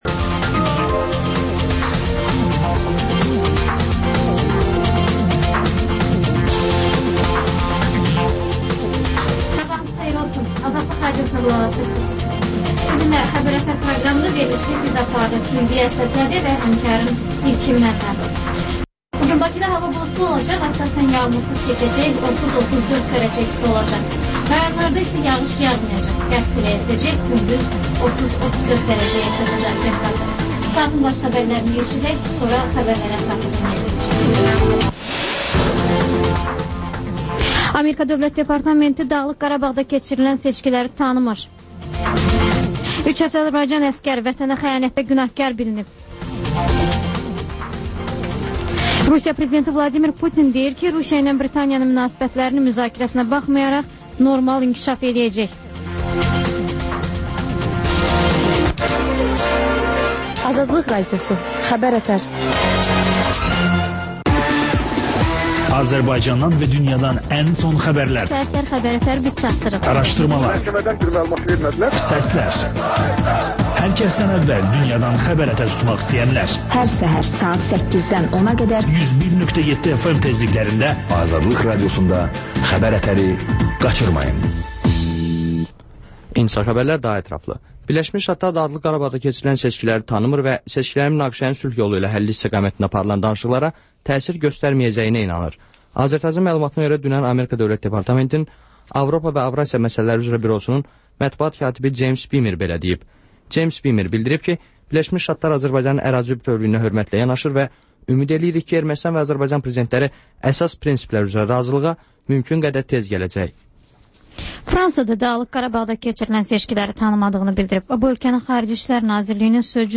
Xəbər-ətər: xəbərlər, müsahibələr və TANINMIŞLAR verilişi: Ölkənin tanınmış simalarıyla söhbət